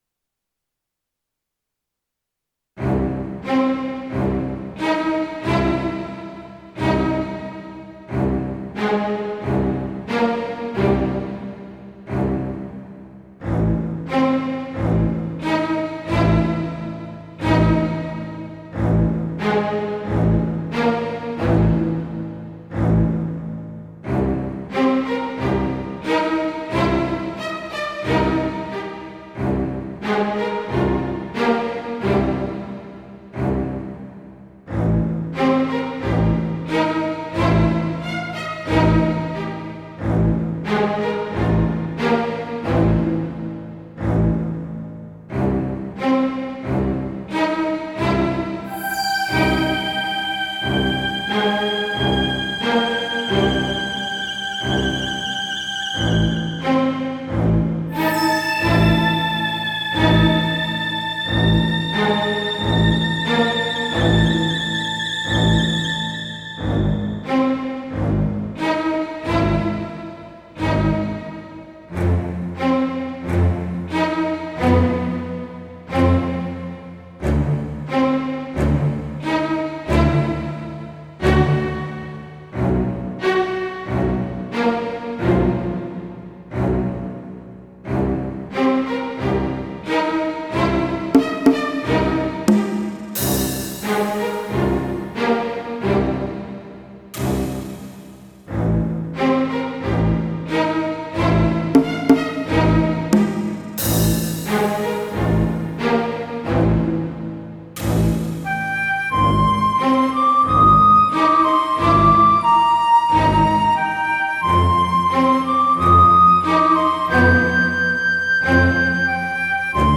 MIDI remix